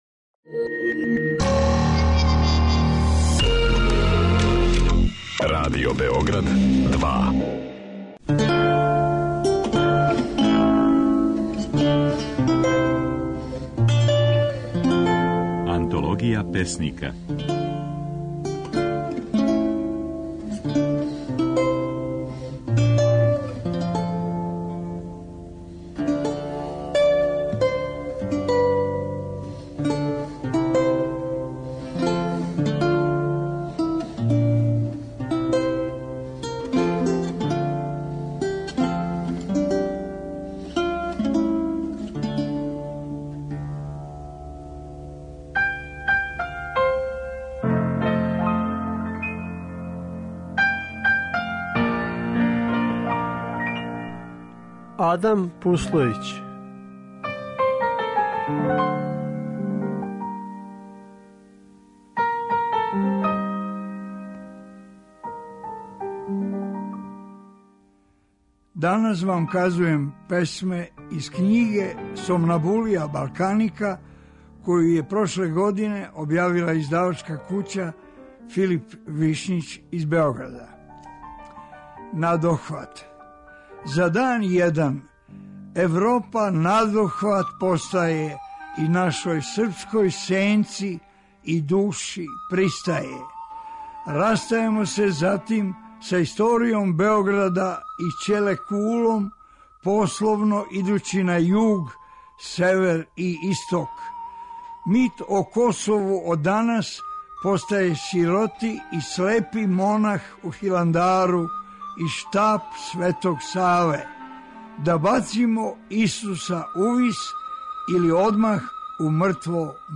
Oд 29. маја до 2. јуна, можете чути како своје стихове говори песник Адам Пуслојић (1943, Кобишница код Неготина).